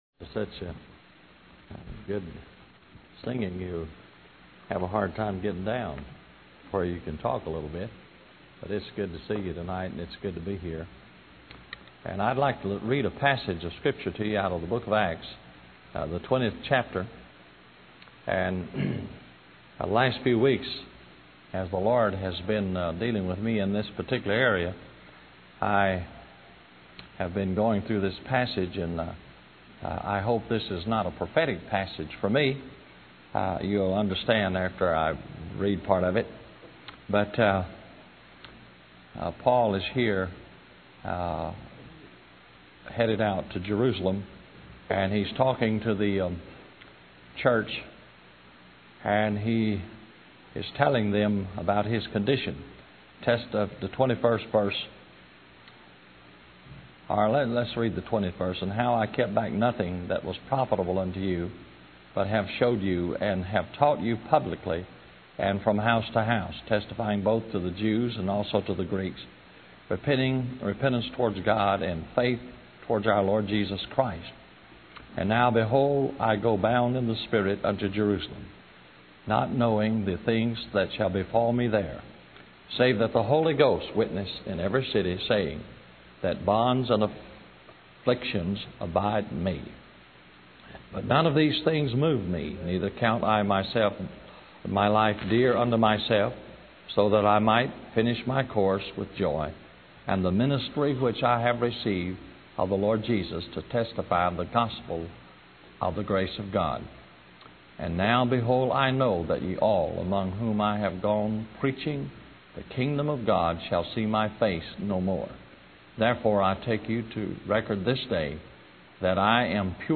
In this sermon, the preacher emphasizes the importance of accepting Jesus Christ as Lord and Savior. He warns that those who do not believe in Jesus will face judgment at the great white throne.